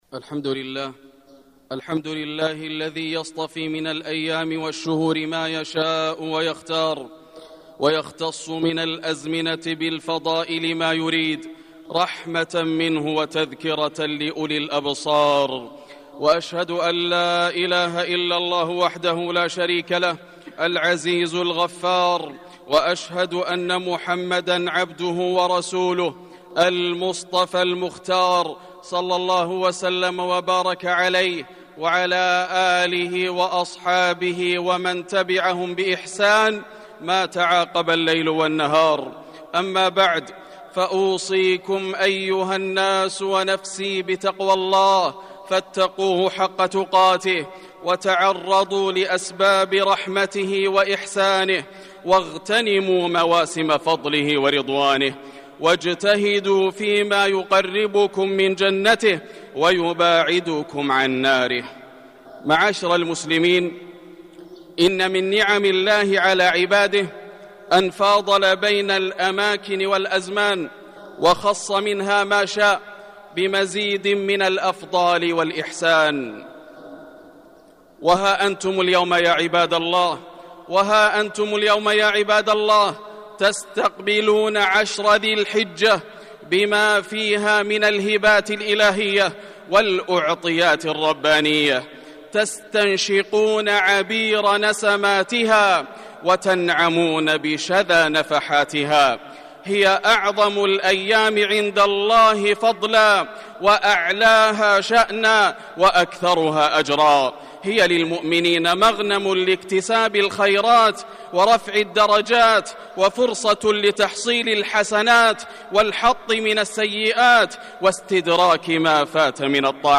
مكة: فضل وأعمال الأيام العشر من ذي الحجة - ياسر بن راشد الدوسري (صوت - جودة عالية